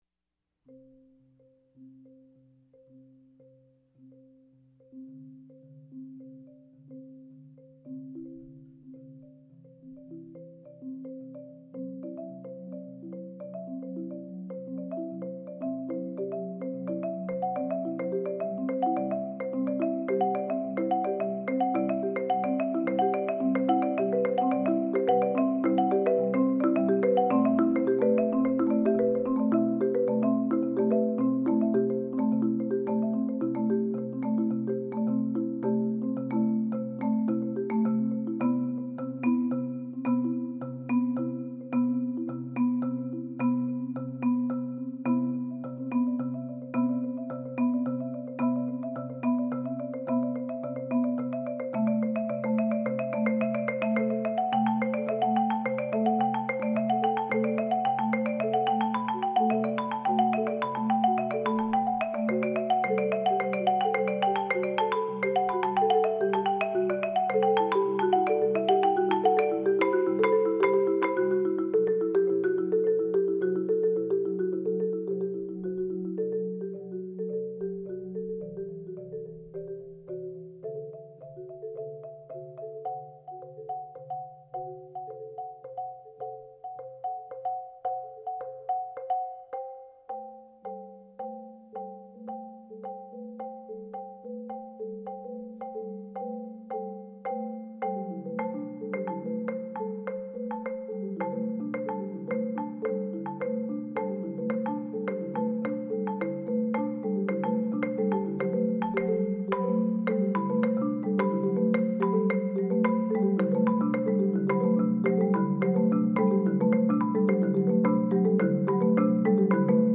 Voicing: Mallet Trio